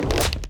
Rock Meteor Throw 2.wav